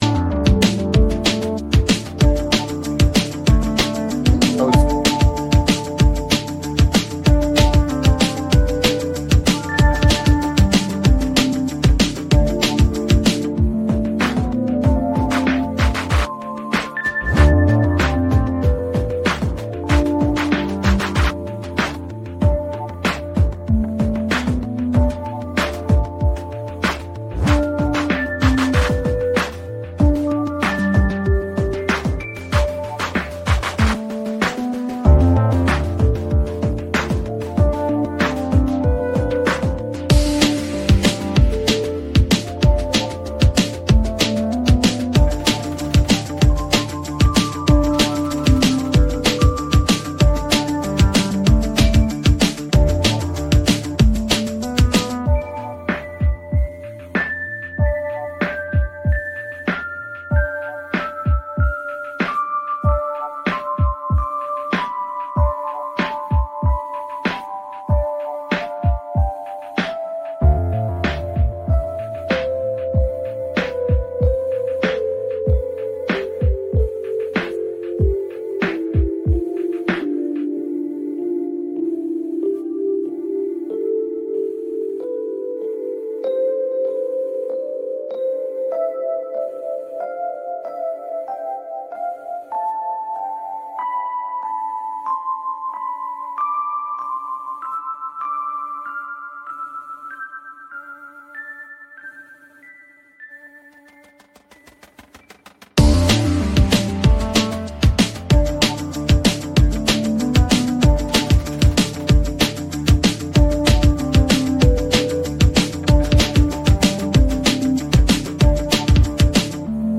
Political cartoonist Ted Rall and CIA whistleblower John Kiriakou deprogram you from mainstream media every weekday at 9 AM EST.